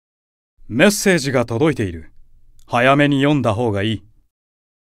Diavolo_Chat_Notification_Voice.ogg